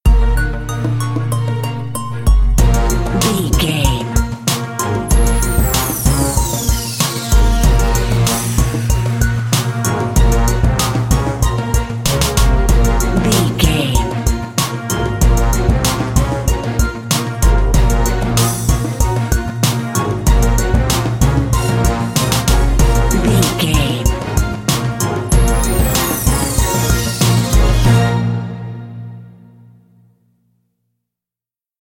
Aeolian/Minor
B♭
drums
percussion
strings
conga
brass
hip hop
Funk
neo soul
acid jazz
energetic
bouncy
Triumphant
funky